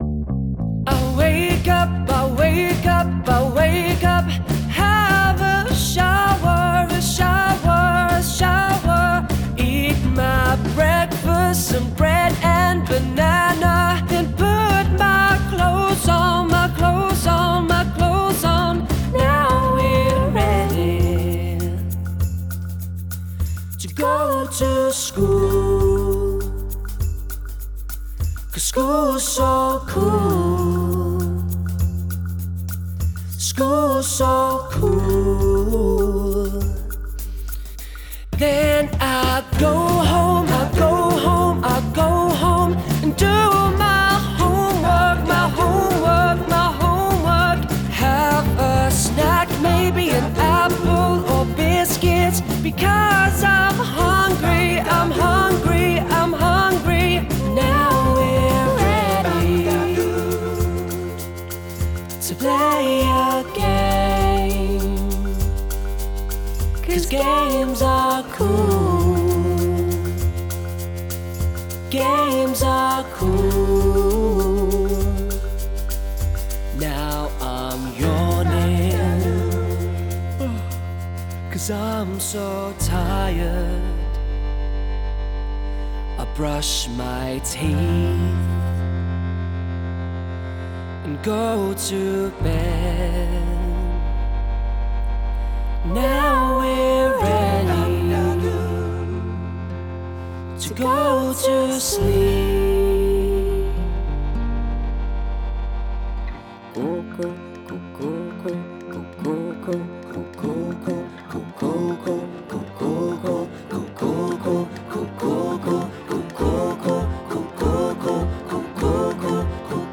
Robot songs